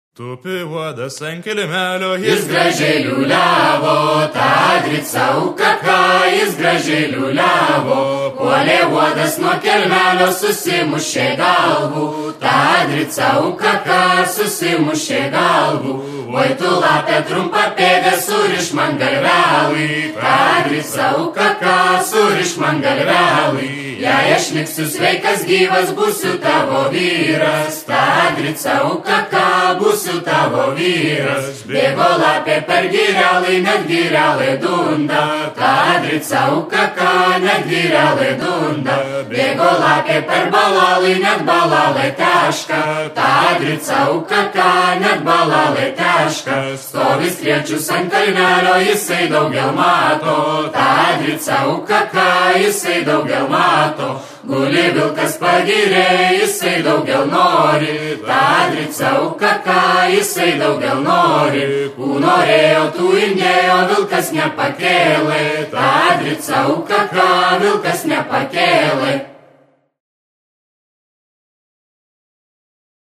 ansamblis